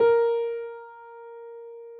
Added more instrument wavs
piano_058.wav